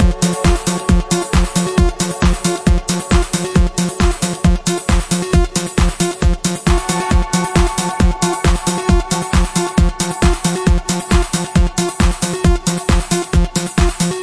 恍惚循环在174 BPM